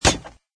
woodmetal2.mp3